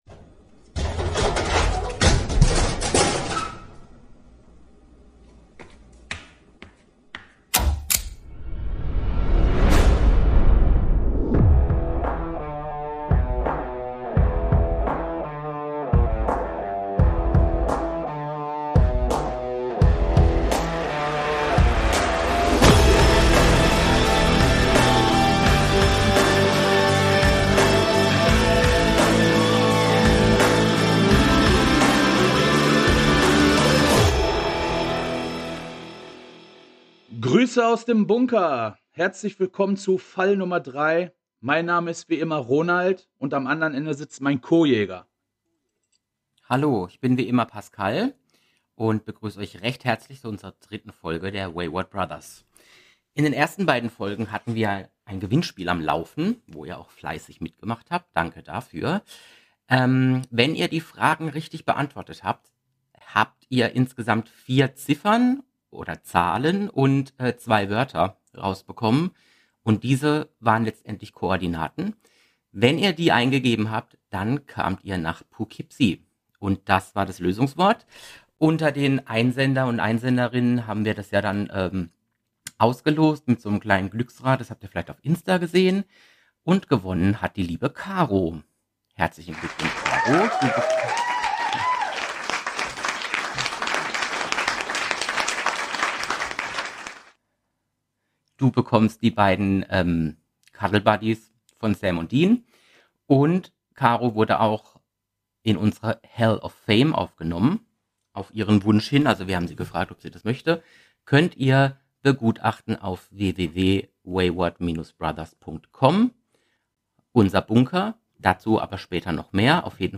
Fall 003 führt uns mitten in den Bunker – diesmal mit einem Blick auf Dean Winchester. Eine ikonische Figur zwischen Humor, Loyalität und inneren Konflikten. Fanbasiert, leidenschaftlich und mit einem Augenzwinkern gesprochen.